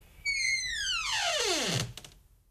Squeaky Door Close